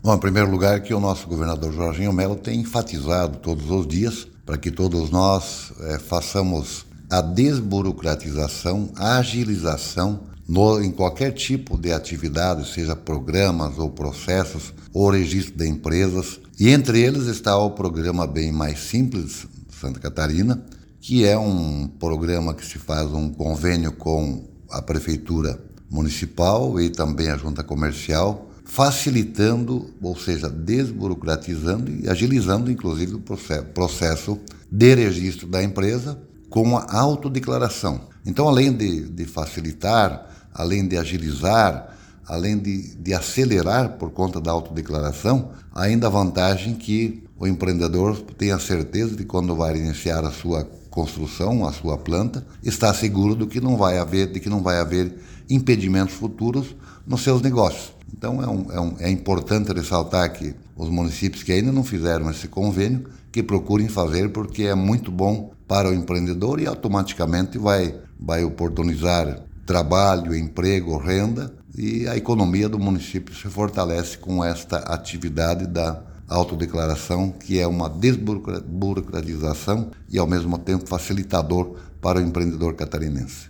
O secretário da Sicos, Silvio Dreveck, explica como funciona o programa e destaca seus benefícios para o empreendedor e para as cidades: